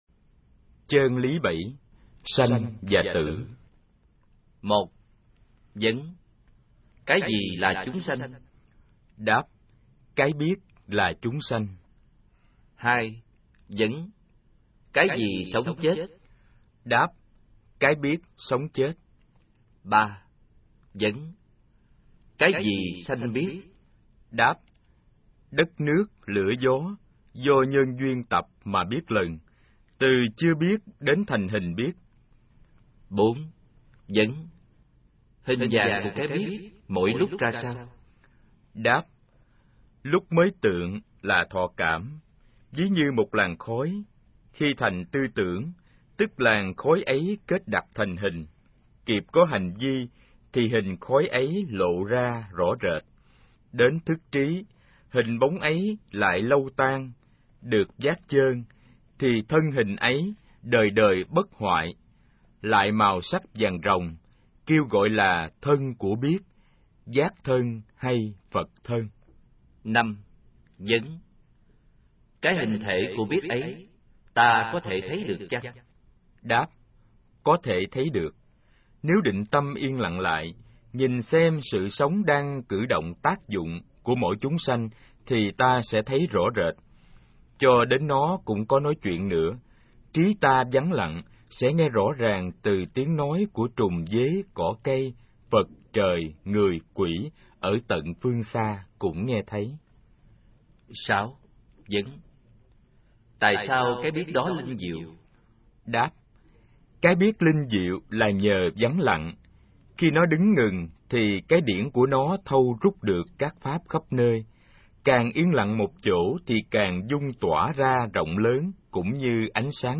Nghe sách nói chương 07. Sanh và Tử